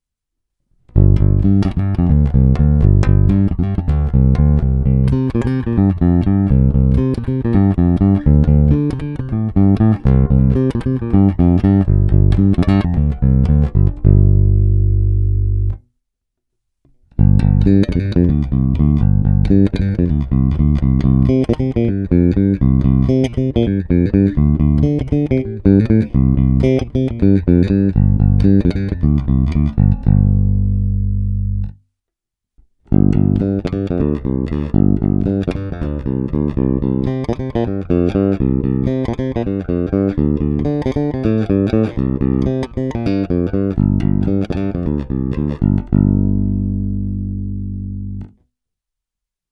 Ukázky jsou nahrány rovnou do zvukové karty a jen normalizovány.